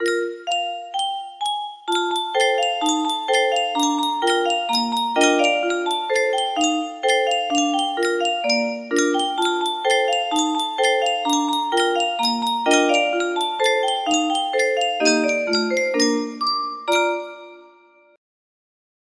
Yunsheng Music Box - IBTWYPDB 5217 music box melody
Full range 60